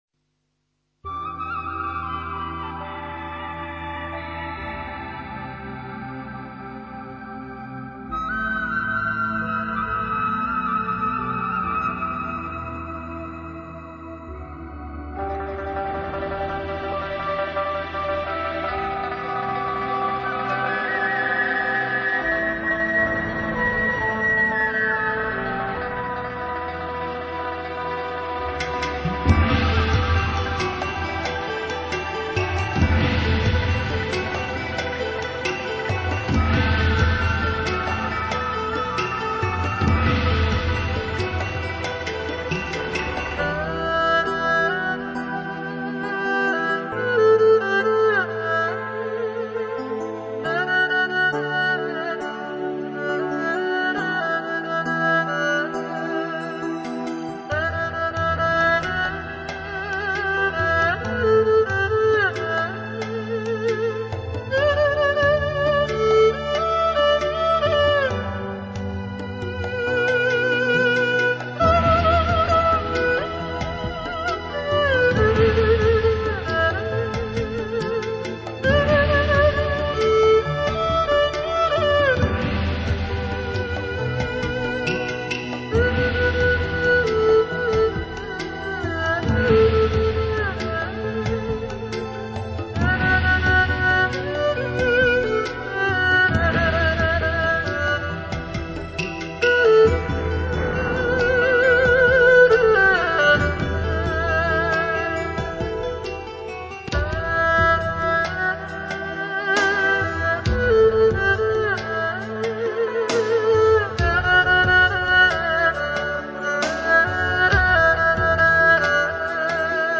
二胡与古筝的终极对话
琴弓与弦丝相融，一如海水拥吻礁石，静似春风，动若波涛，